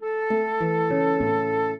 flute-harp
minuet7-6.wav